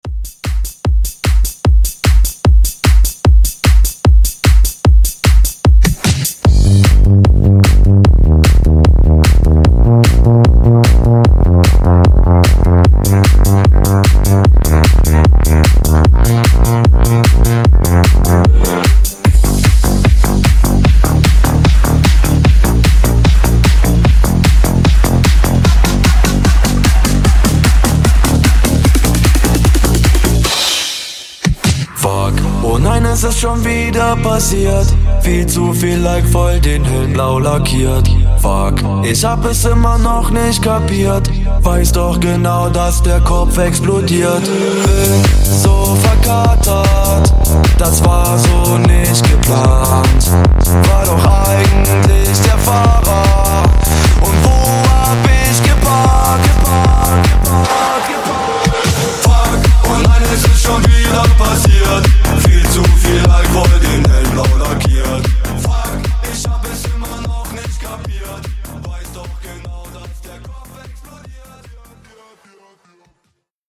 Genres: COUNTRY , RE-DRUM , TOP40
Clean BPM: 80 Time